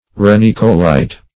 Search Result for " arenicolite" : The Collaborative International Dictionary of English v.0.48: Arenicolite \Ar`e*nic"o*lite\, n. [L. arena sand + colere to cherish or live.]